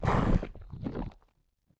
foot_act_1.wav